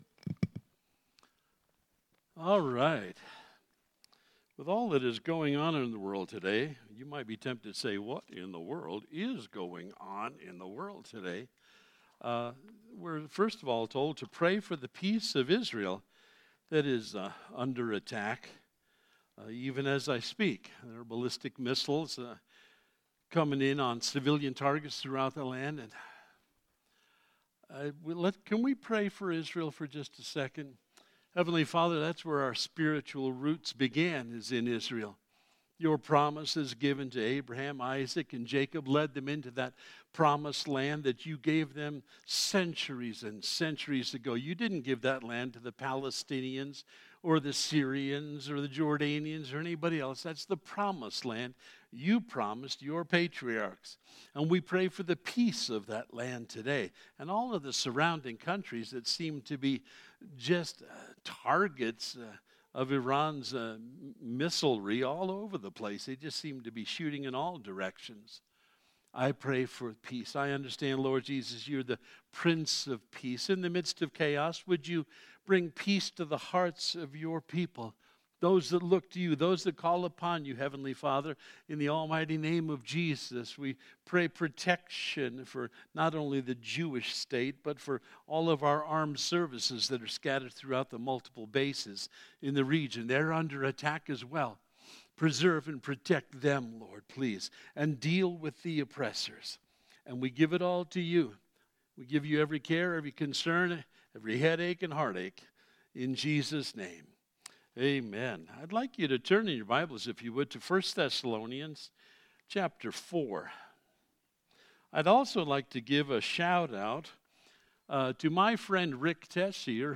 March-1st-Sermon-.mp3